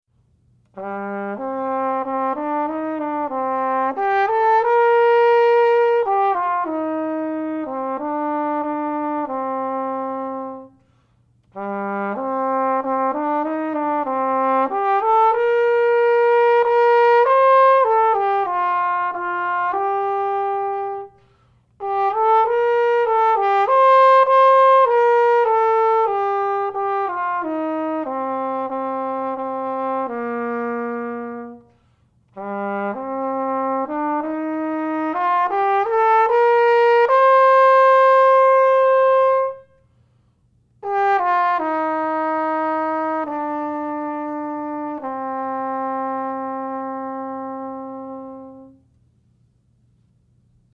to offer some recordings of the audition technical etudes: